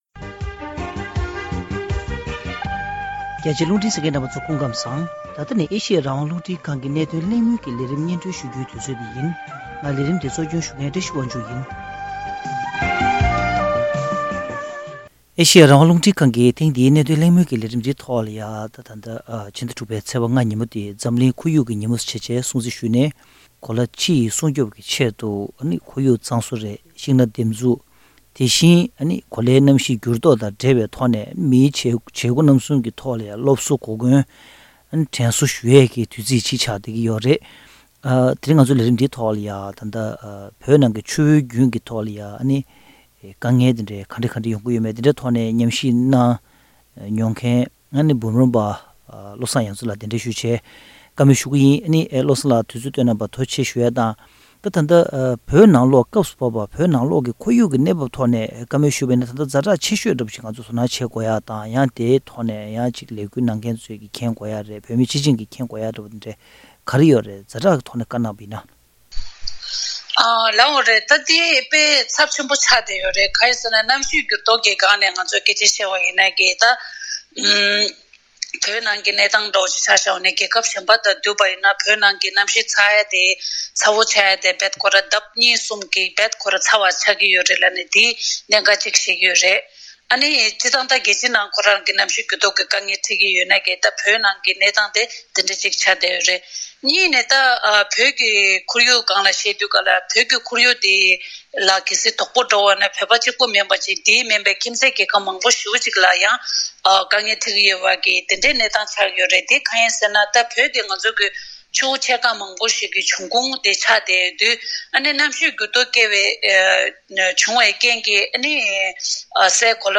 བོད་ནང་གི་ཁོར་ཡུག་གནས་བབས་ཐད་ཉམས་ཞིབ་པ་དང་ལྷན་གླེང་མོལ་ཞུས་པ།།